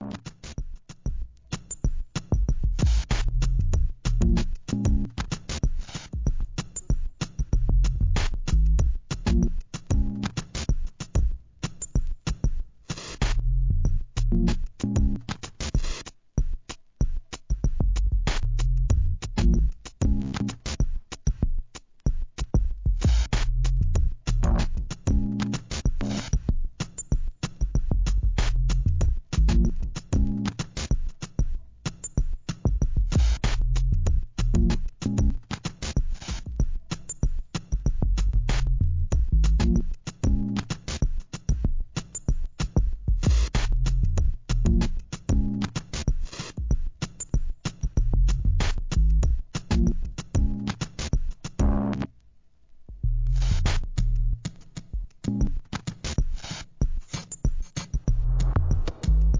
Electronic, Minimal